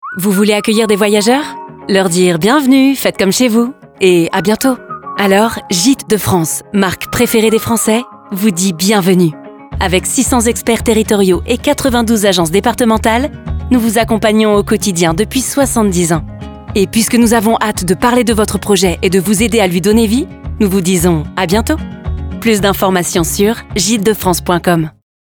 Gites de France Packshot PUB RADIO next previous Campagne Radio